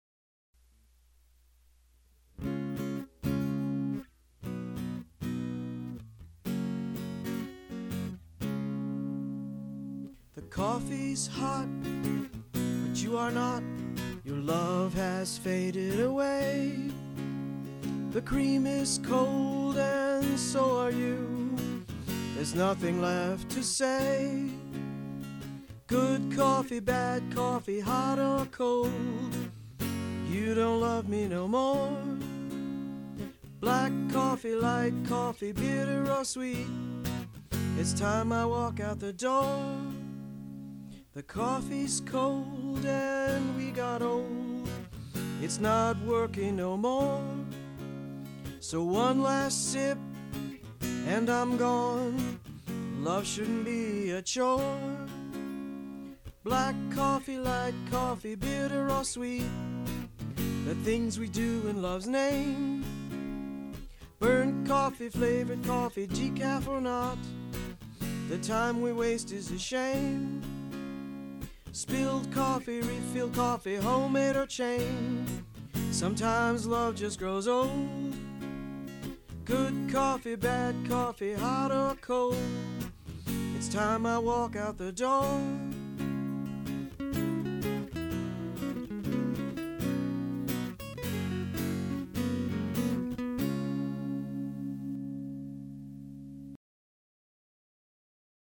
Politically Incorrect Modern Blues